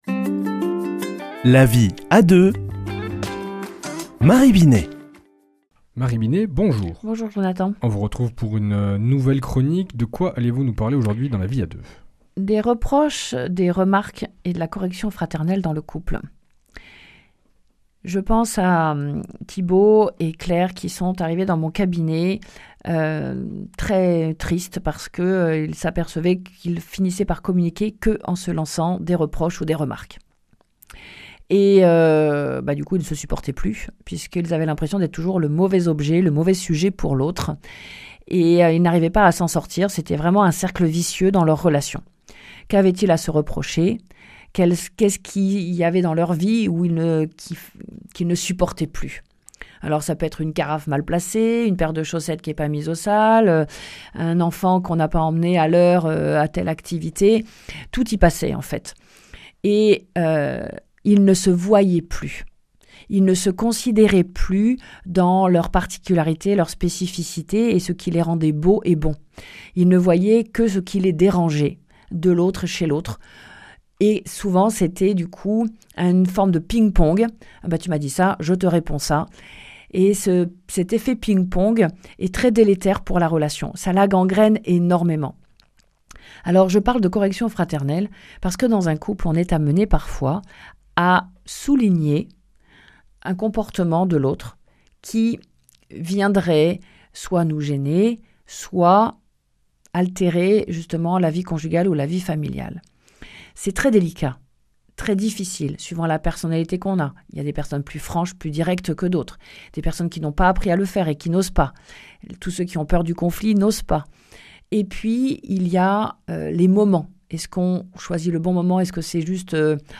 mardi 25 novembre 2025 Chronique La vie à deux Durée 4 min